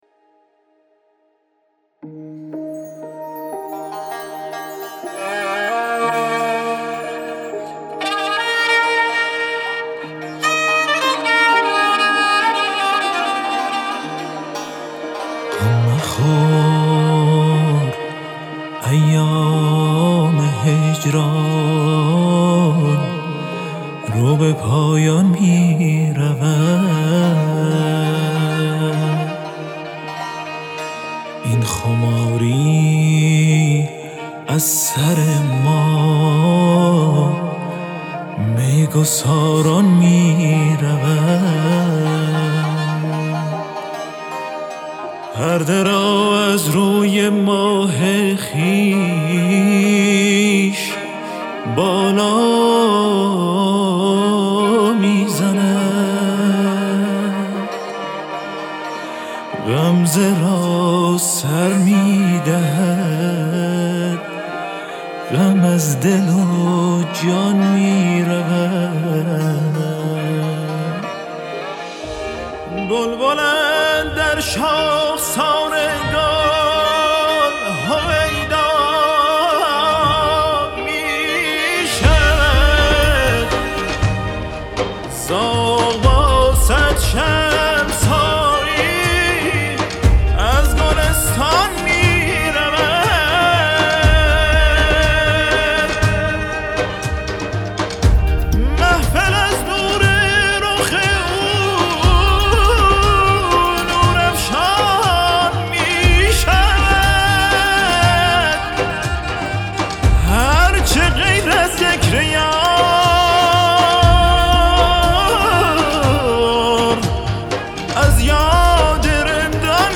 اثری عاشقانه عرفانی